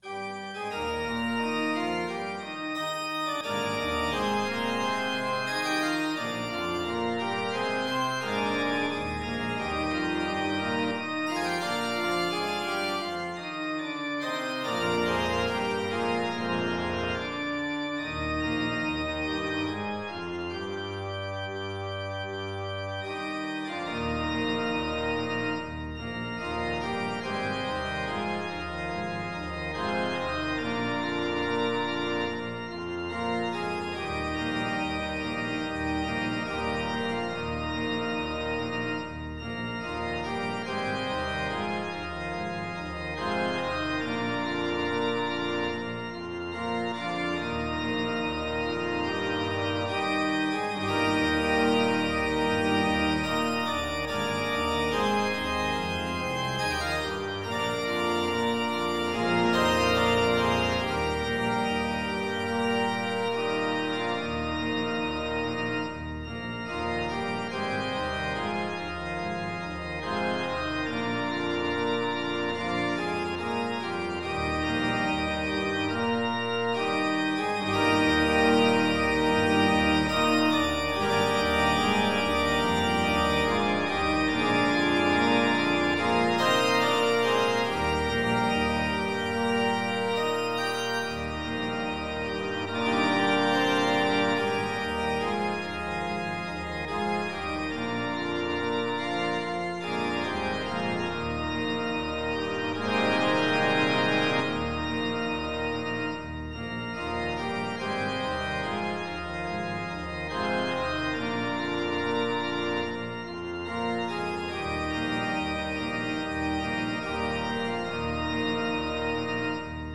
SATB
Voicing/Instrumentation: SATB We also have other 4 arrangements of " Let Us Oft Speak Kind Words ".